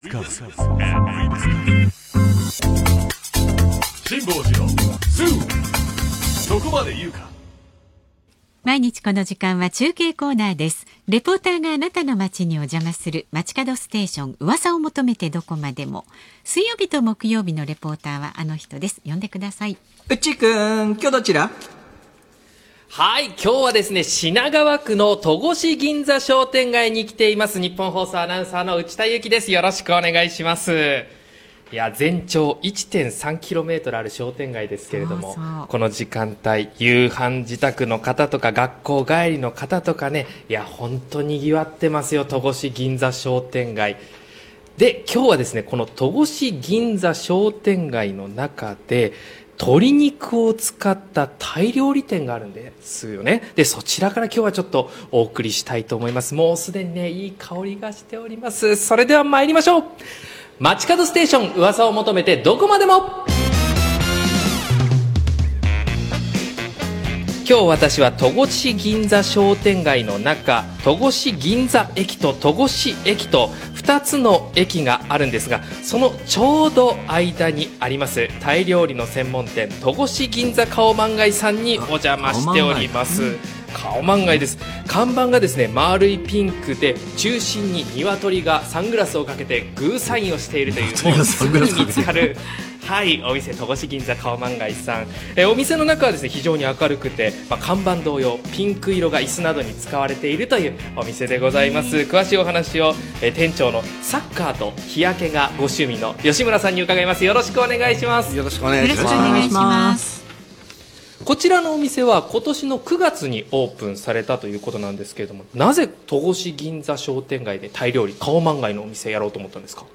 当日のラジオ音声です。